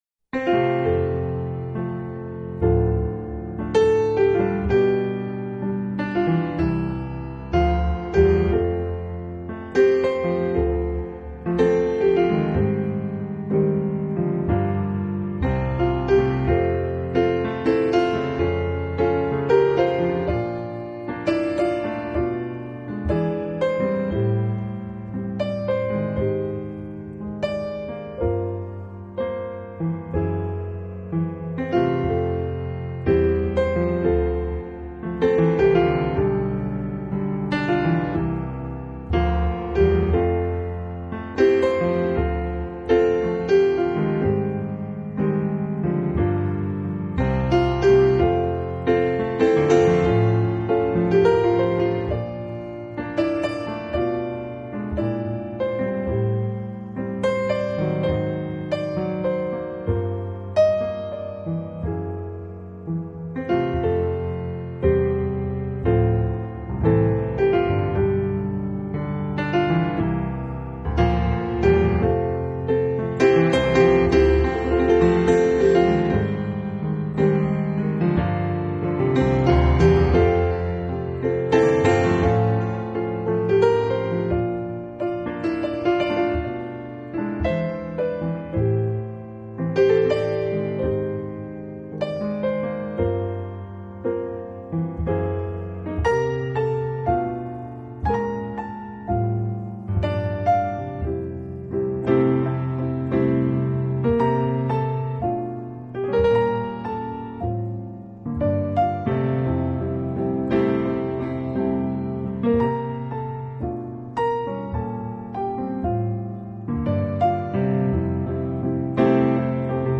【钢琴纯乐】